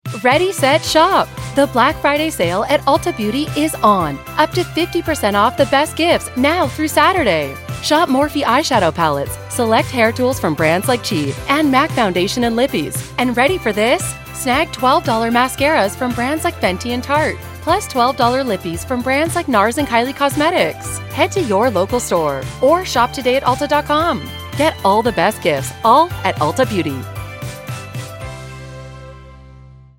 Here are some Black Friday audio ad samples that were heard across the world this year: